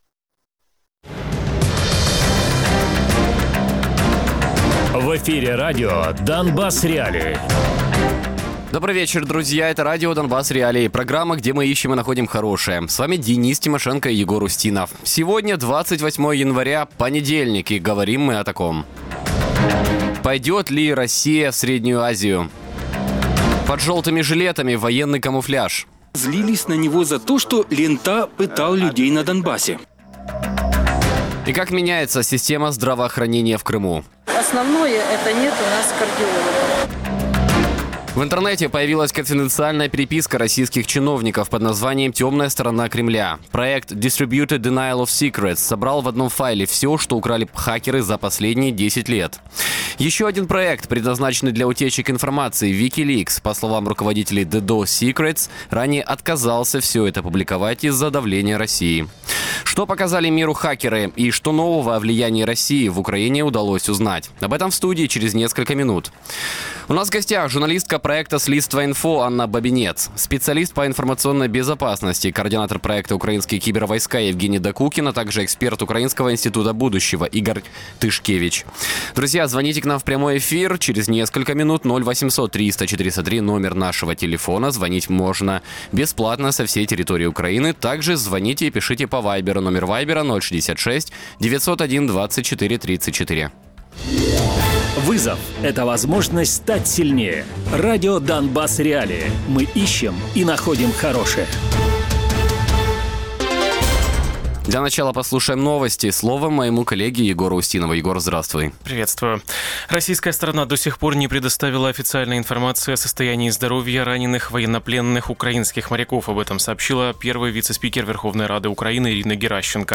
Радіопрограма «Донбас.Реалії» - у будні з 17:00 до 18:00.